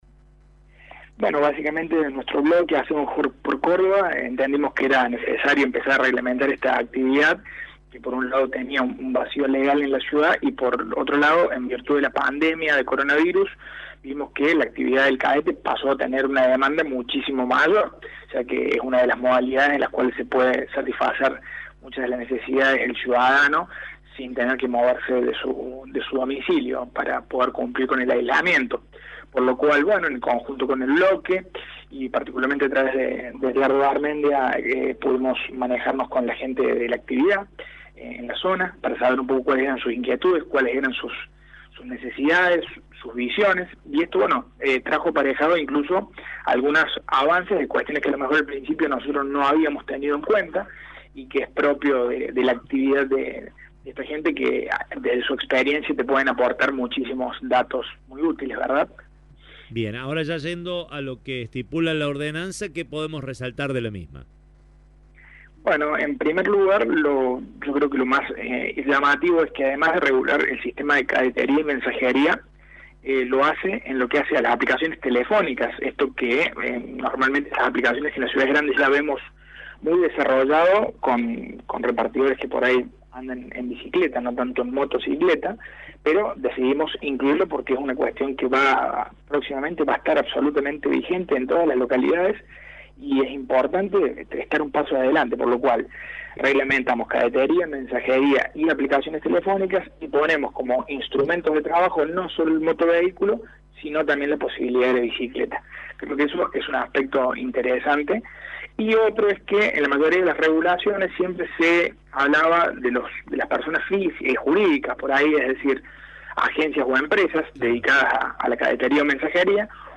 CONCEJAL-VILLANOVENSE.mp3